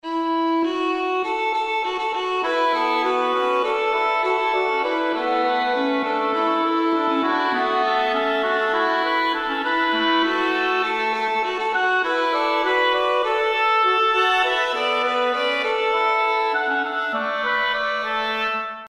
Музыка для театра